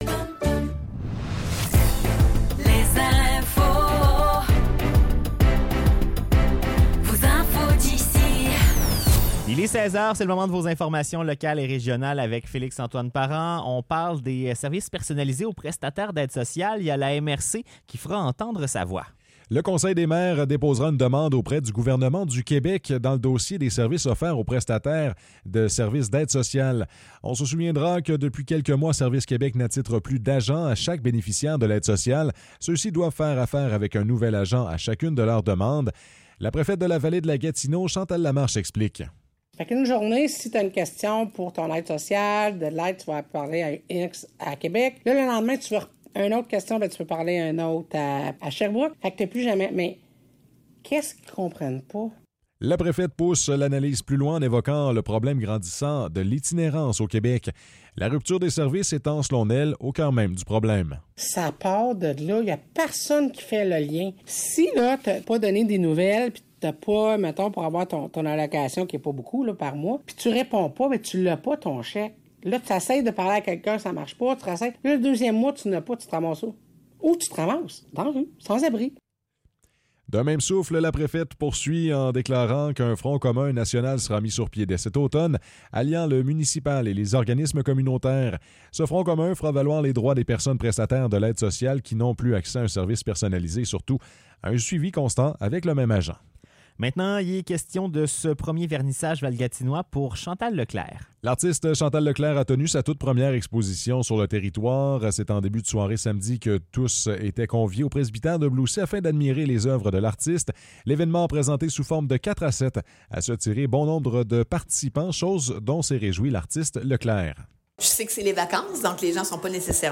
Nouvelles locales - 29 juillet 2024 - 16 h